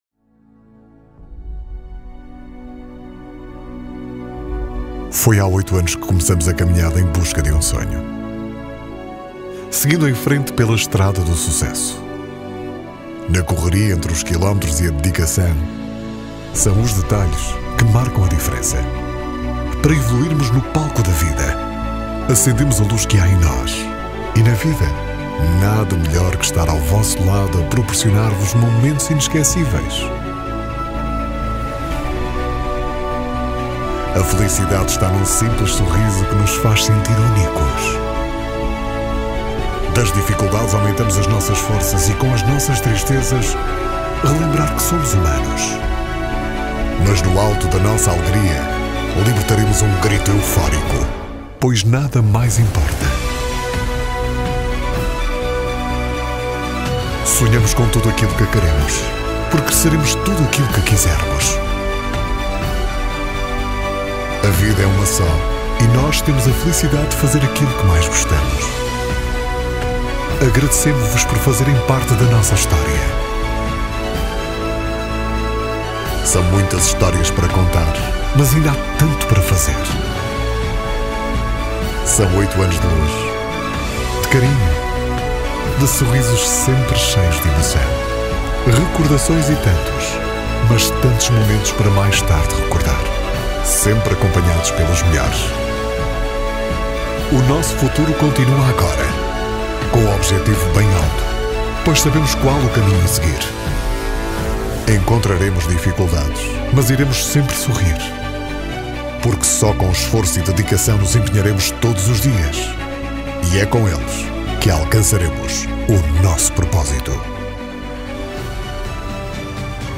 Locuções Institucionais e Comerciais
DEMO Locuções Institucionais e Comerciais
Locuções-Institucionais-e-Comerciais.mp3